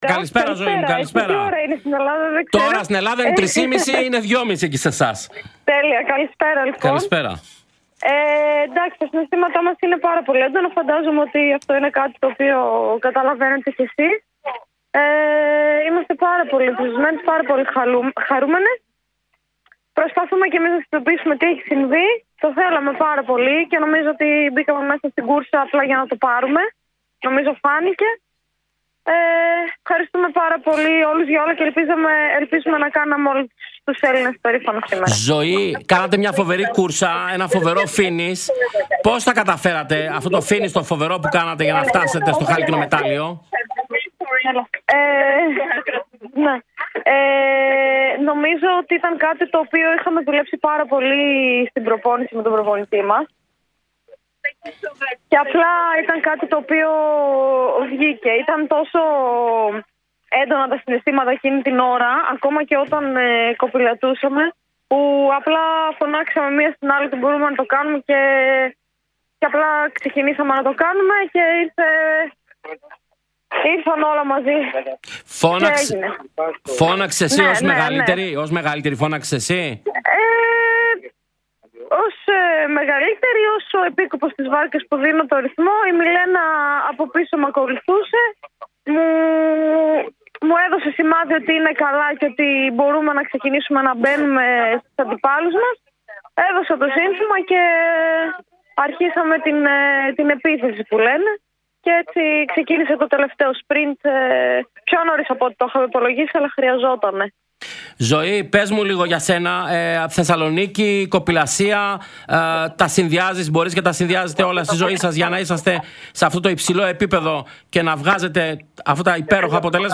Τις πρώτες τους δηλώσεις ύστερα από την κατάκτηση του χάλκινου μεταλλίου στην κωπηλασία στους Ολυμπιακούς Αγώνες 2024 έκαναν στον Real fm 97.8 και την εκπομπή Real Sports